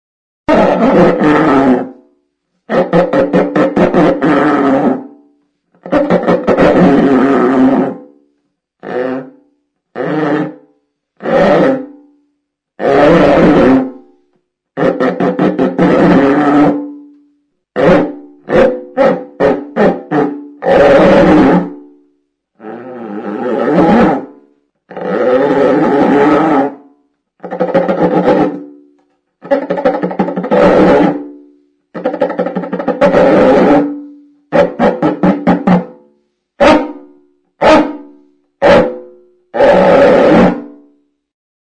Membranophones -> Frictionnés -> Corde
Enregistr� avec cet instrument de musique.